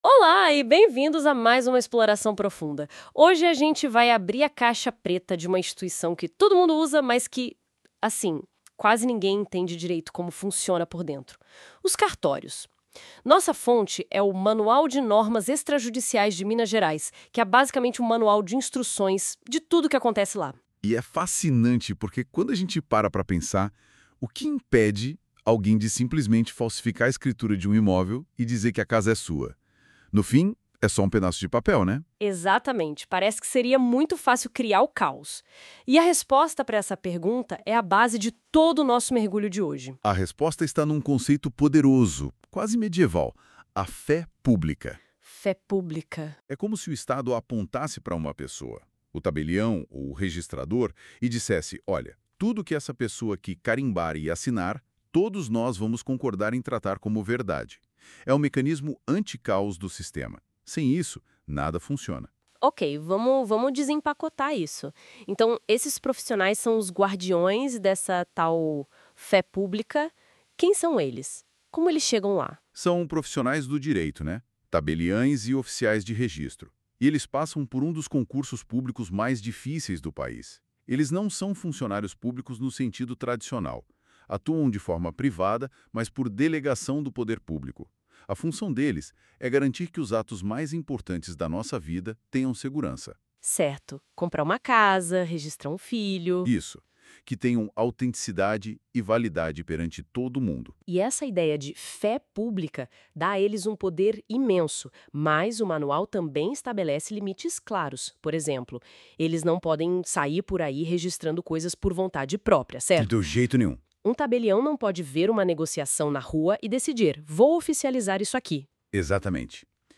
Código de Normas MG – Livro I – Parte Geral (Áudio): 30 Aulas em Podcast: Mais de 6 horas de conteúdo narrado com clareza (IA), abrangendo todos os eixos da Parte Geral.
Aula-1-O-Essencial-sobre-Cartorios-em-Minas-Gerais-new.mp3